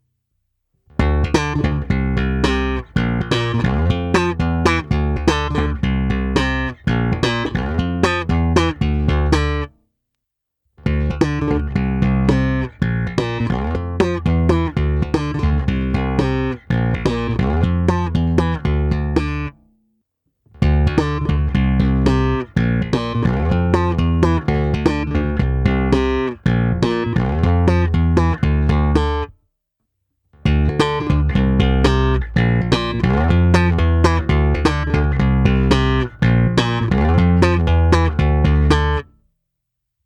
Postupně je v ukázkách čistý zvuk baskytary bez kompresoru, pak kompresor s režimy v pořadí NORMAL, MB a nakonec TUBESIM.
To samé, ale se simulací aparátu, kdy jsem použil impuls boxu Ampeg 8x10".
Ukázka slapu + IR